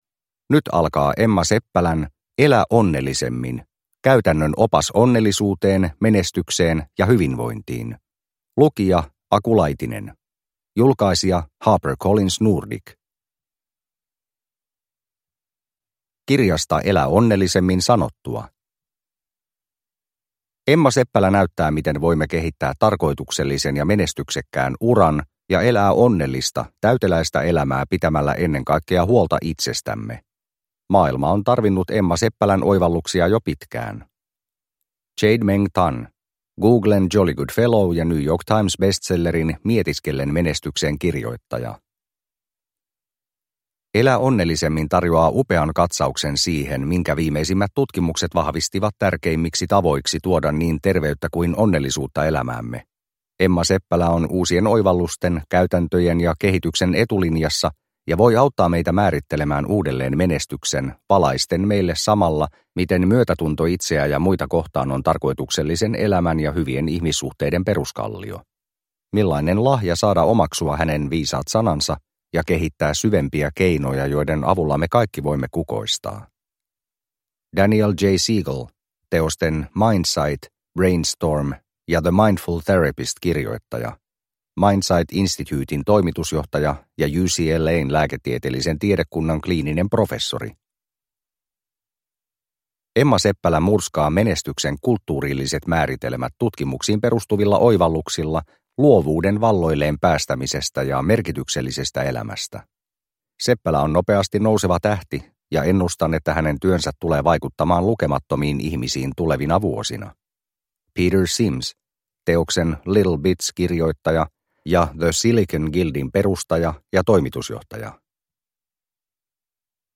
Elä onnellisemmin – Ljudbok – Laddas ner